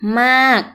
maag